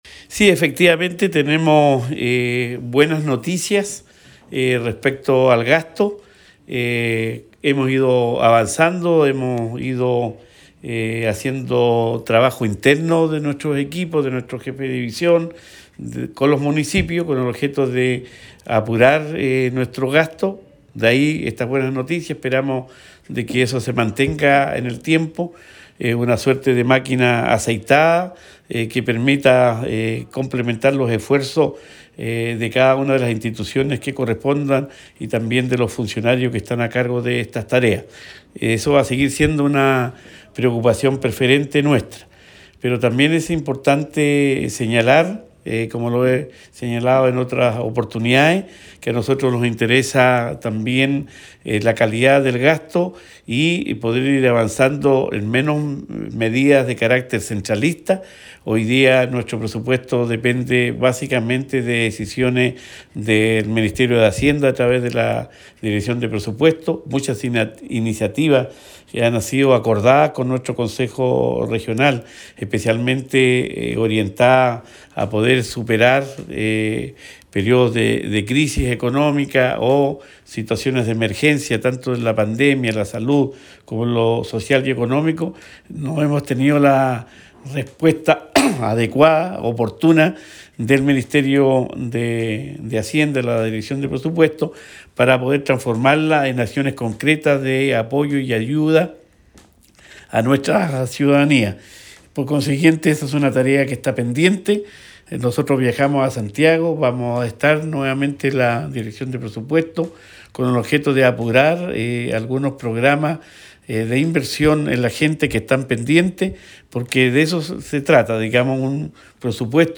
Cuña_Gobernador-Cuvertino_ejecución-presupuestaria.mp3